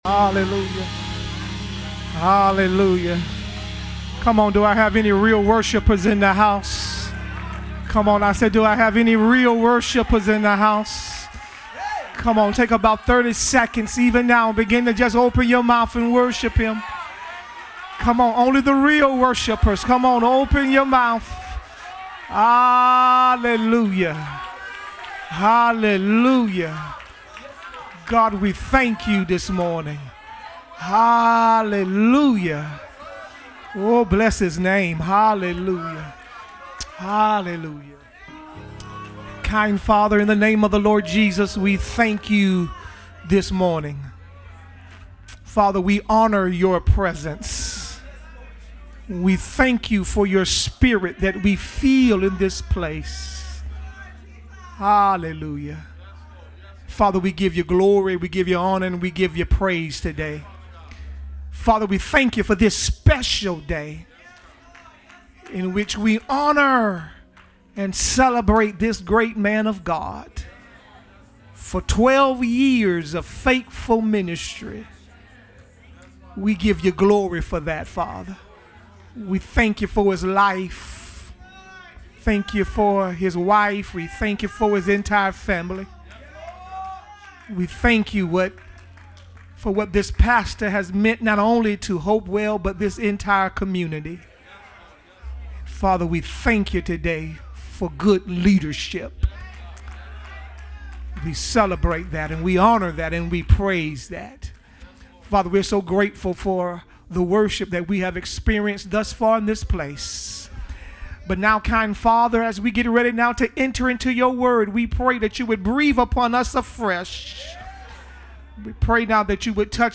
Hopewell Missionary Baptist Church, Carbondale IL
audio sermon